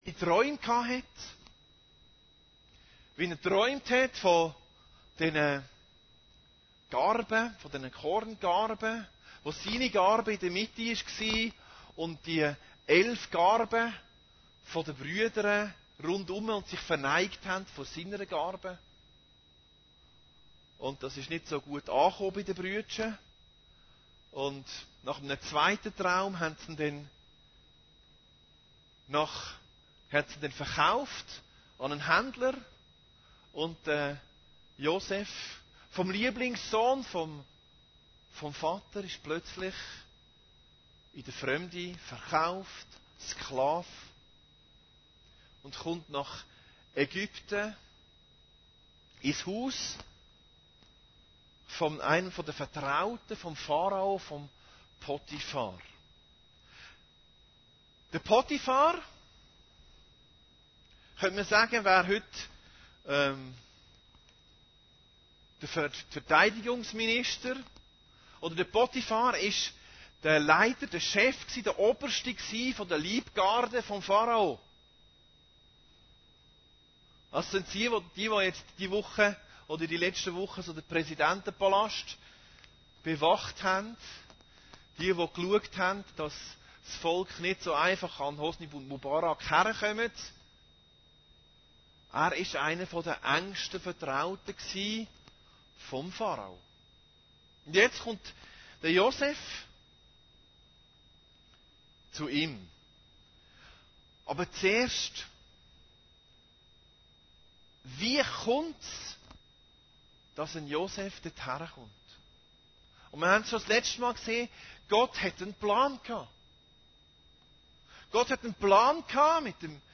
Predigten Heilsarmee Aargau Süd – Aufstieg